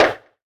step1.ogg